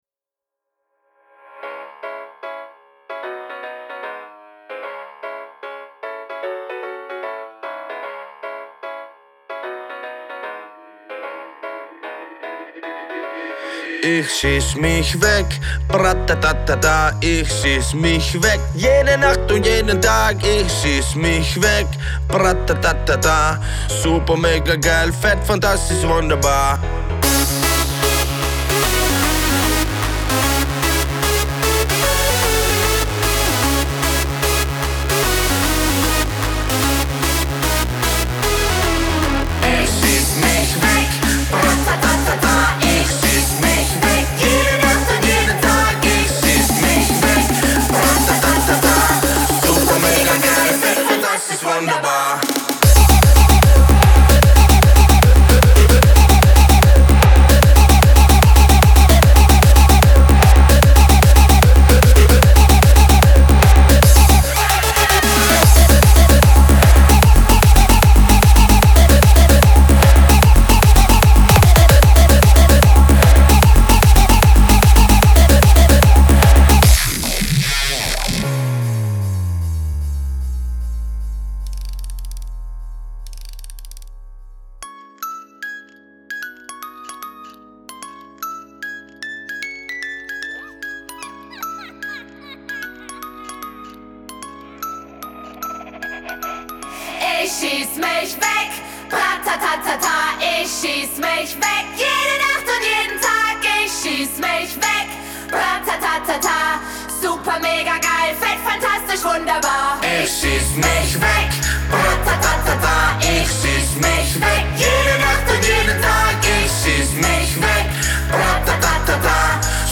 Genre: House, Trance, Electronic, Dance.